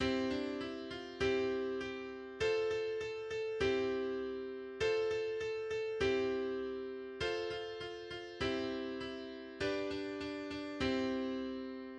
Kinderreim